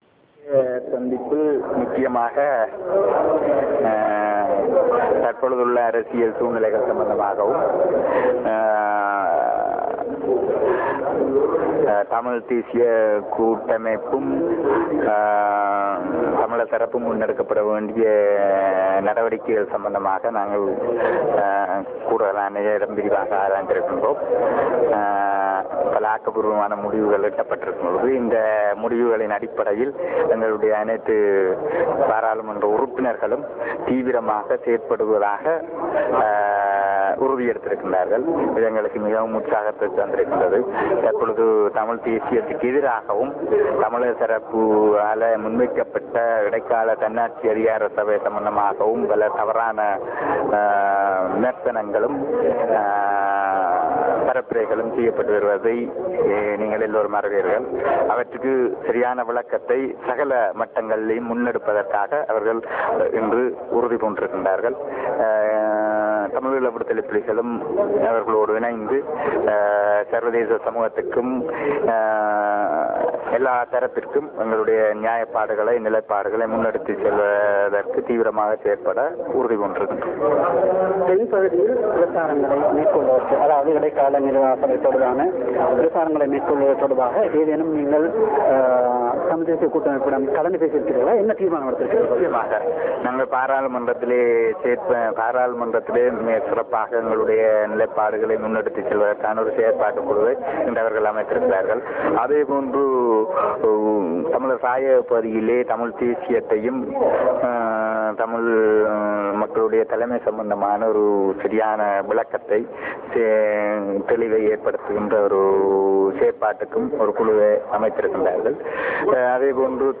LTTE Political Head Mr. S. P. Thamilchelvan and Mr. Sampanthan met press briefly after TNA's meeting with the LTTE's Political Head at the Planning Secretariat of the Liberation Tigers in Kilinochchi.
Real Audio Icon Press briefing by Mr. S. P. Thamilchelvan (Tamil)